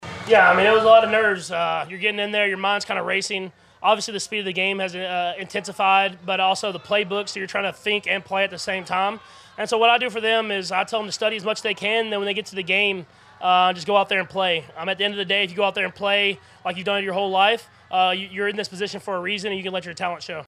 Quarterback Patrick Mahomes says he’s giving advice to newcomers.
8-11-mahomes-advice-to-young-players.mp3